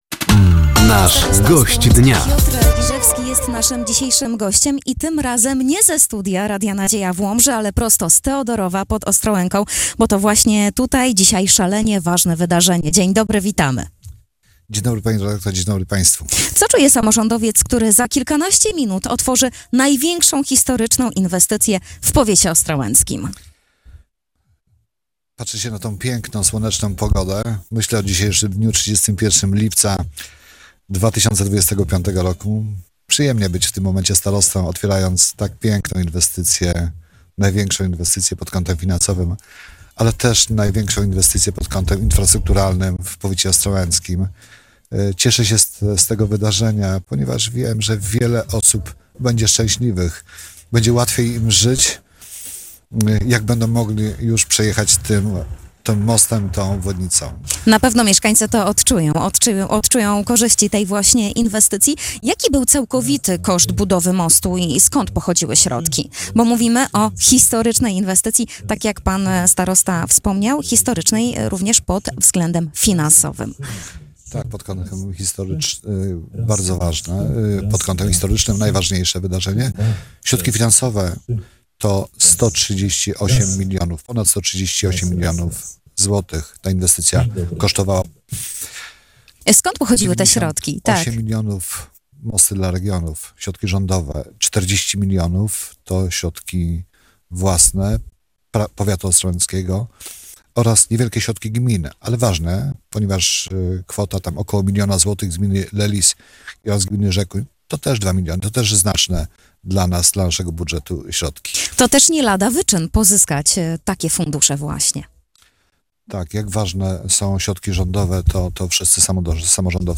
Gościem Dnia w czwartek (31.07) był starosta ostrołęcki Piotr Liżewski. Samorządowiec opowiadał o oddaniu tego dnia do użytku największej inwestycji w historii powiatu, a więc Mostu w Teodorowie.